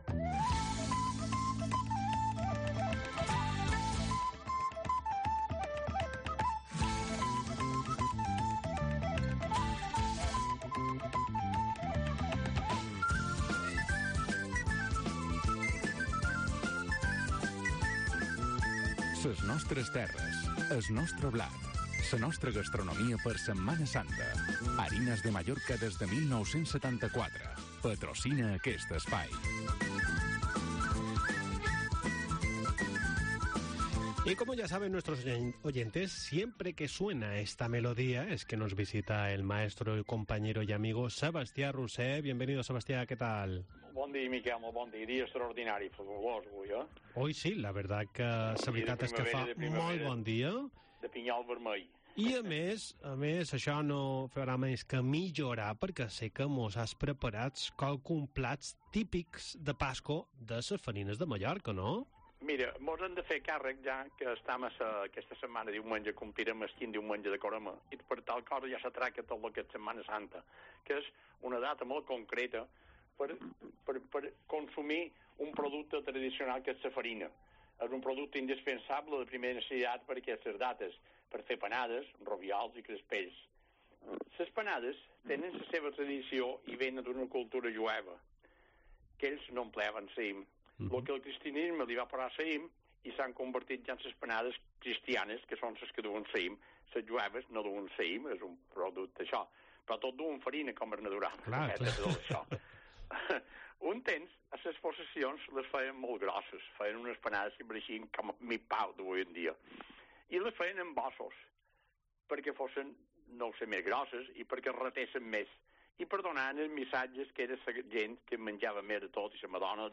Espacio semanal donde repasamos las costumbres mallorquinas y os contamos las ferias y fiestas de los próximos días. Entrevista en 'La Mañana en COPE Más Mallorca', jueves 23 de marzo de 2023.